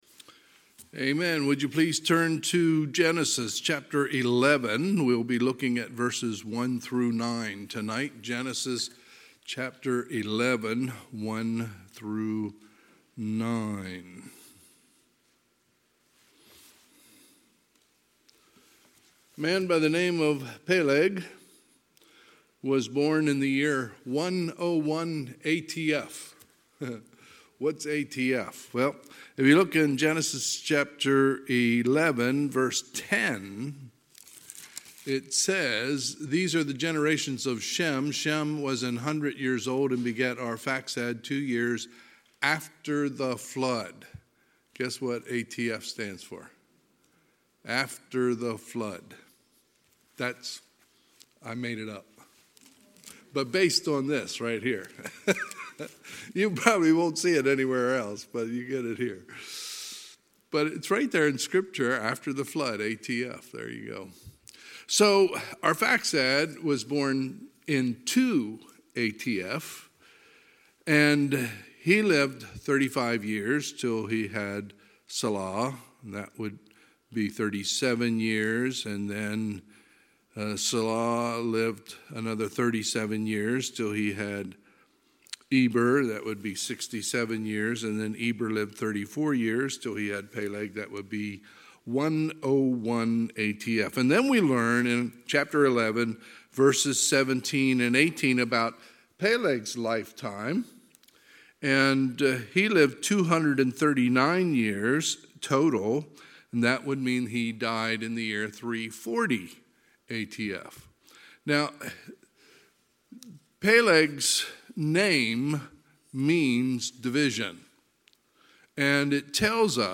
Sunday, April 10, 2022 – Sunday PM
Sermons